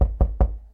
sounds_door_knock_02.ogg